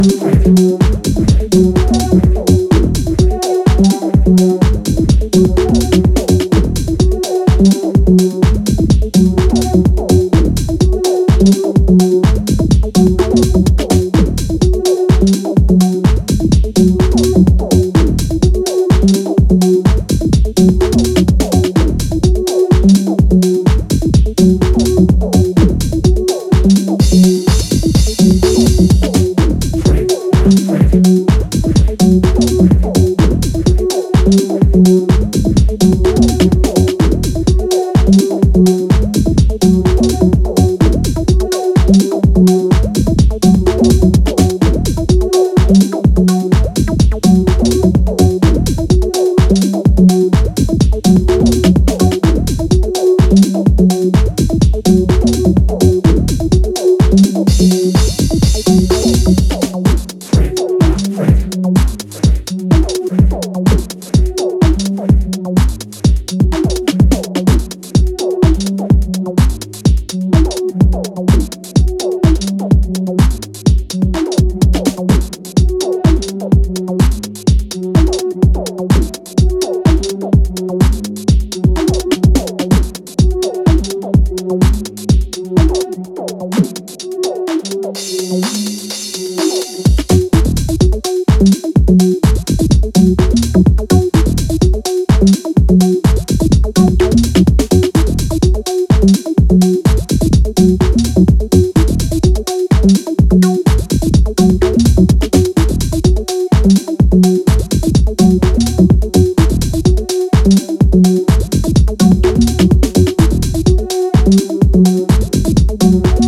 but with extra weight for today’s clubs